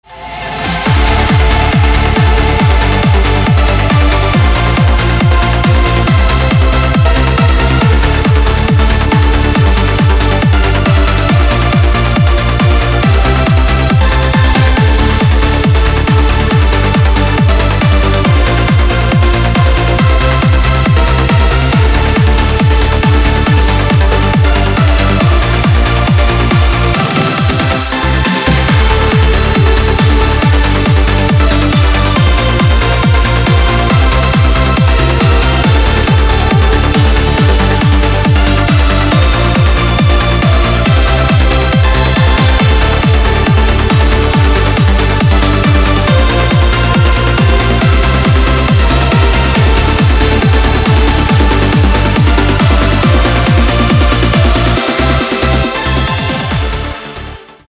• Качество: 256, Stereo
громкие
dance
Electronic
EDM
электронная музыка
без слов
клавишные
club
Trance
быстрые
Hard Trance